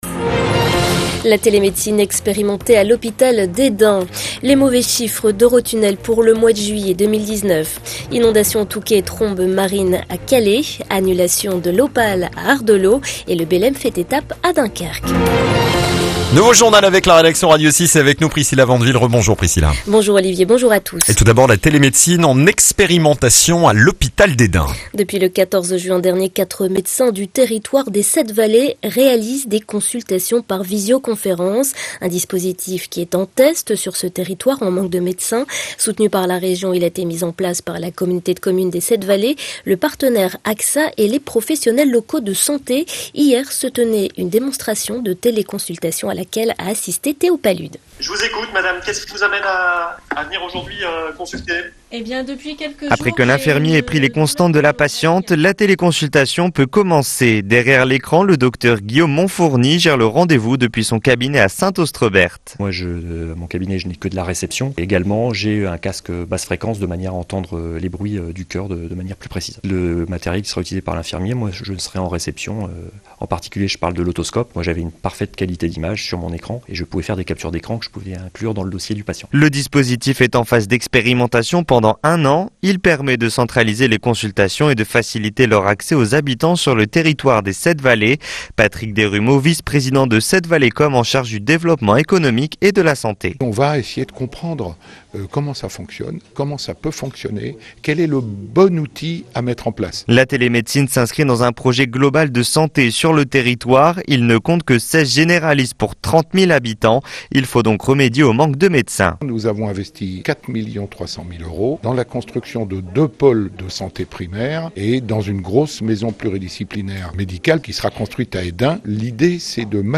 Le journal de 9h du mardi 13 août 2019